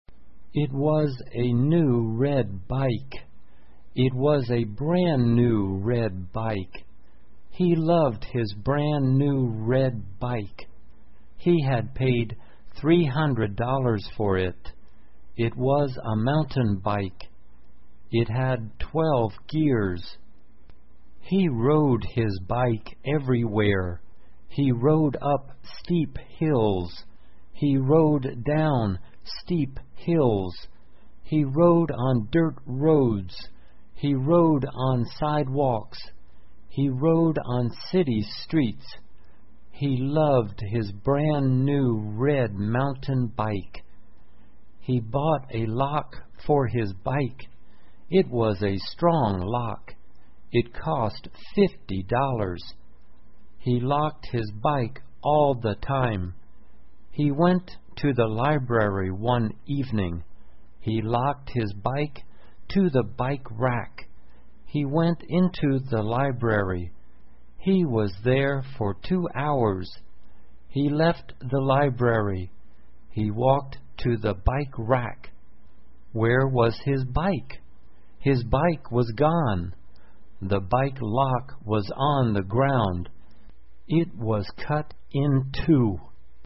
慢速英语短文听力 崭新的红色自行车 听力文件下载—在线英语听力室